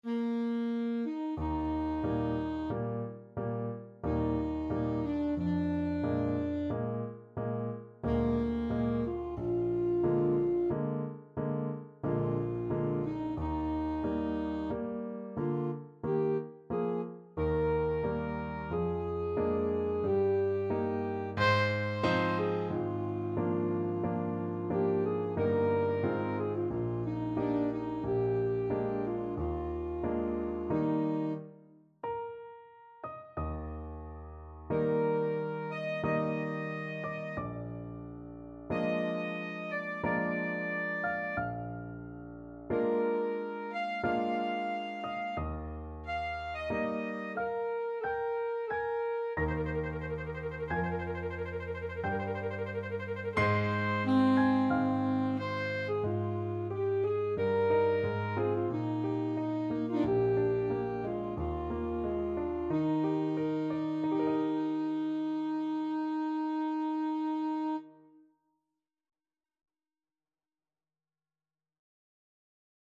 Alto Saxophone
3/4 (View more 3/4 Music)
Adagio =45
Bb4-F6
Classical (View more Classical Saxophone Music)